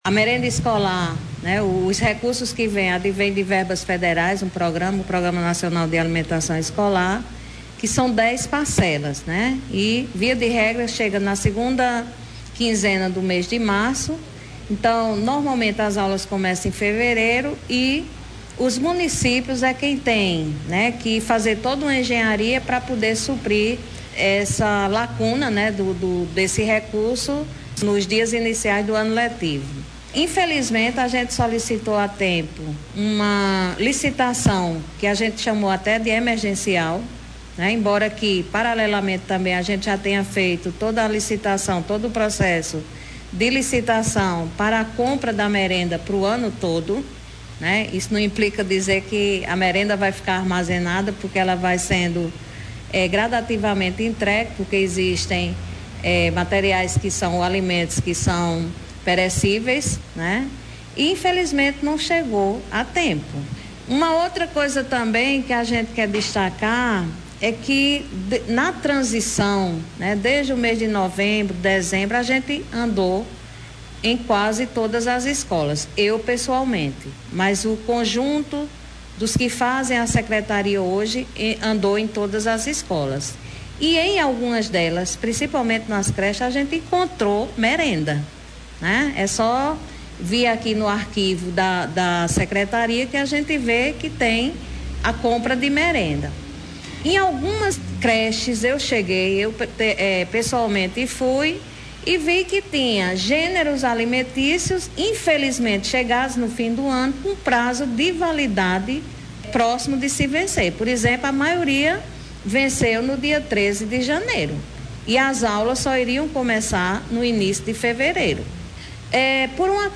A Secretária de Educação do município de Patos, Alana Candeia, concedeu entrevista nesta quinta-feira, 16, onde esclareceu alguns acontecimentos registrados nos primeiros dias letivos do primeiro ano do governo Dinaldinho Wanderley.